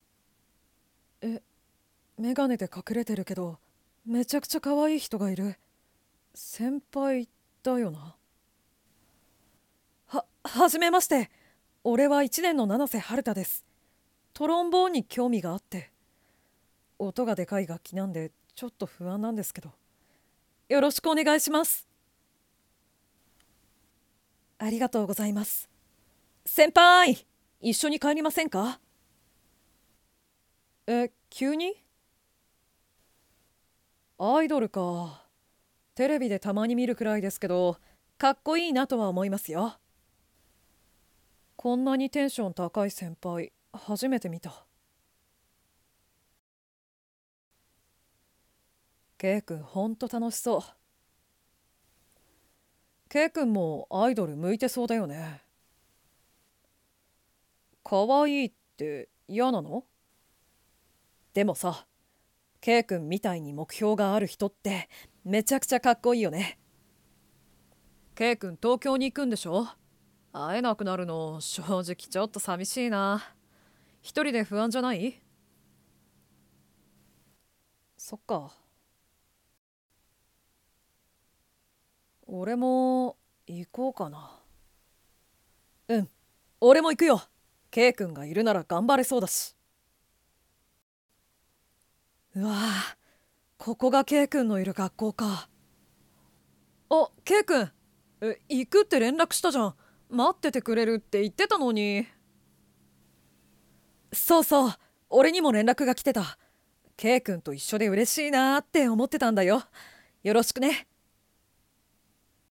声劇②